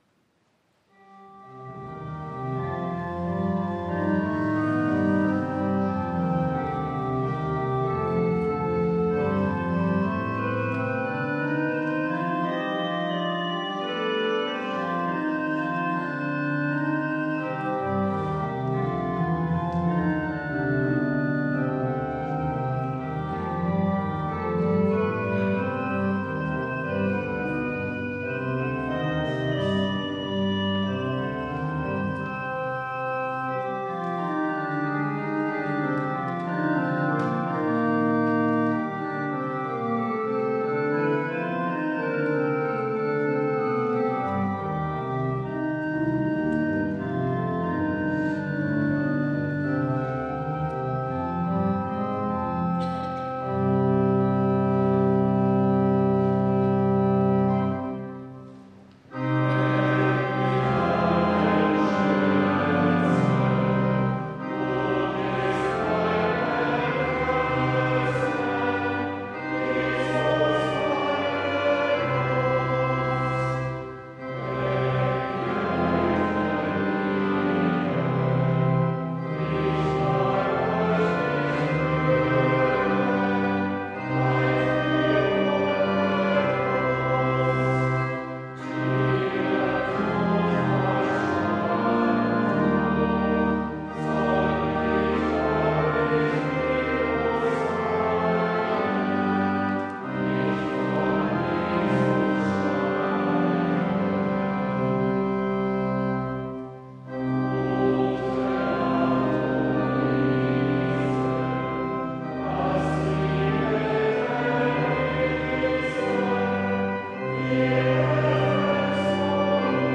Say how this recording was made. Audiomitschnitt unseres Gottesdienstes vom Sonntag Lätare 2024